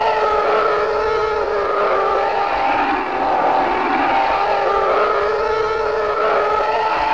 distance.wav